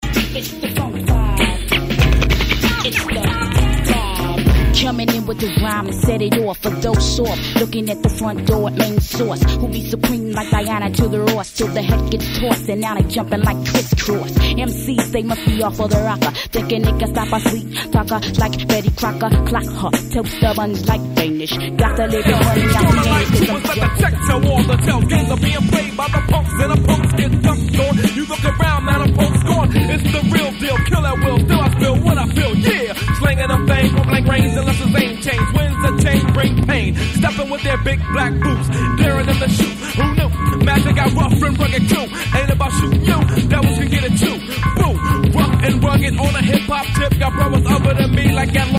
female rapper